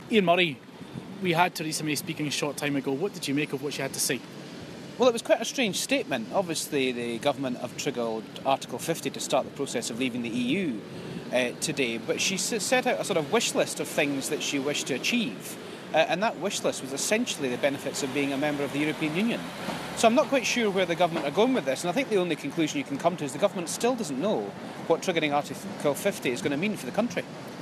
caught up with Scotland's only Labour MP, Ian Murray, outside Westminster: